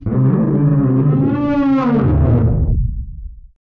MOAN EL 01.wav